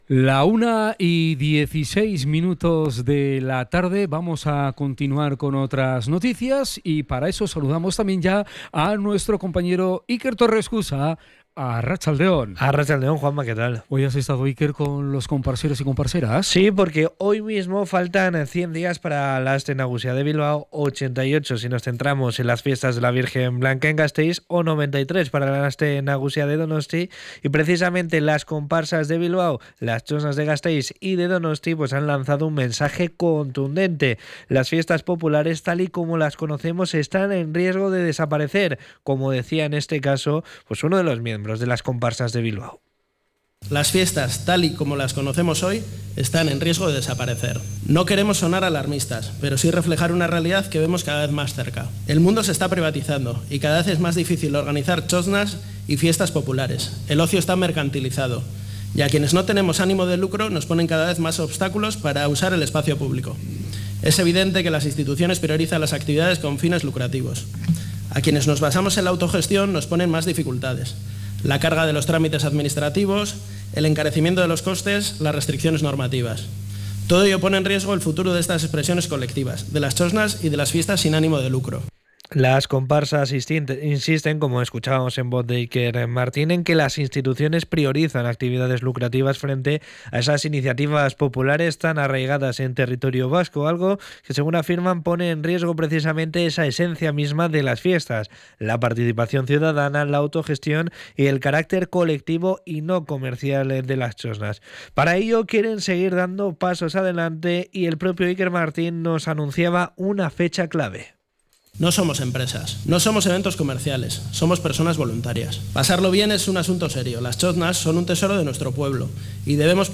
CRONICA-KONPARTSAK.mp3